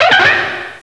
pokeemerald / sound / direct_sound_samples / cries / karrablast.aif
karrablast.aif